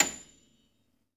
53d-pno29-A6.aif